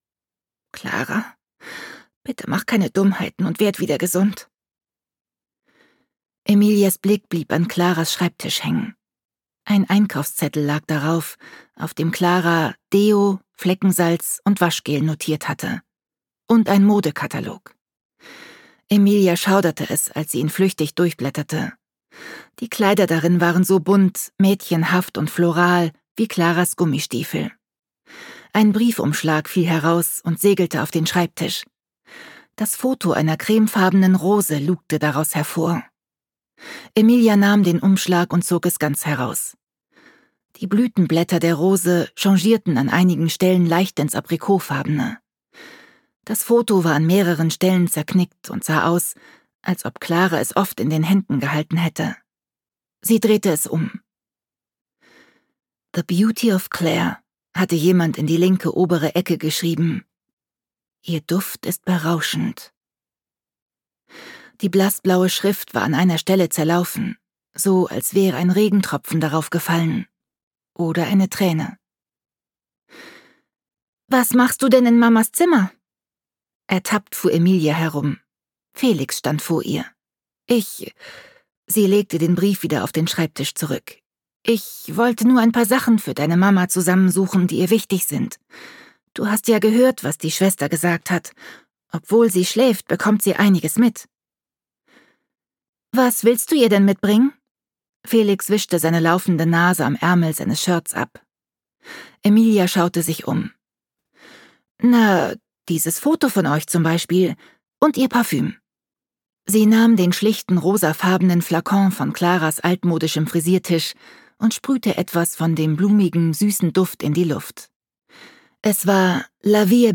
Autorisierte Lesefassung